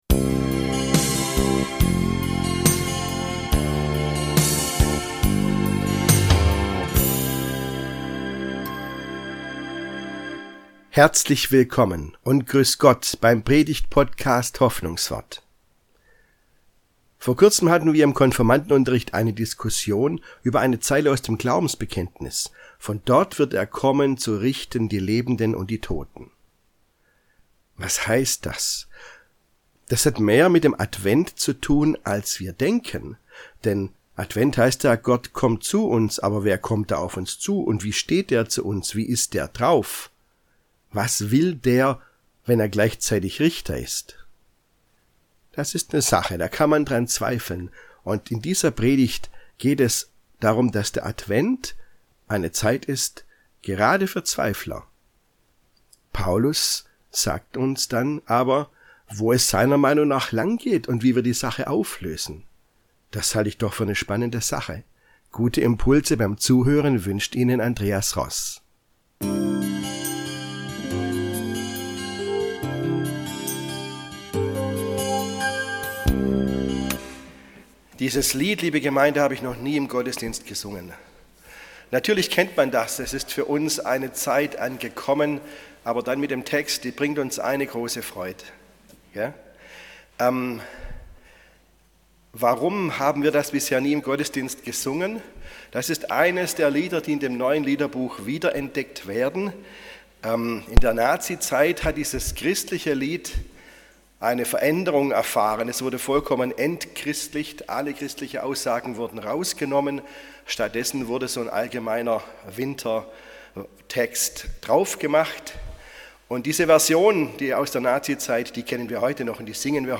Advent für Zweifler ~ Hoffnungswort - Predigten